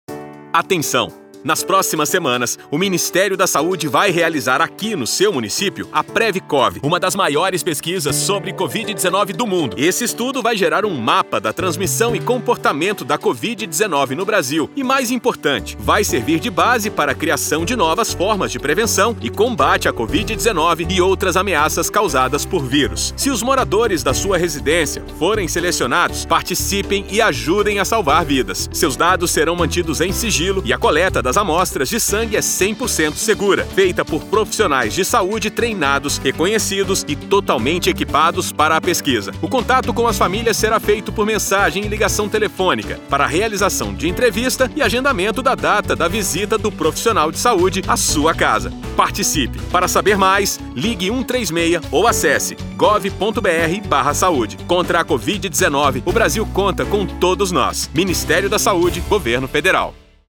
Spot - PrevCOV Carro de Som .mp3 — Ministério da Saúde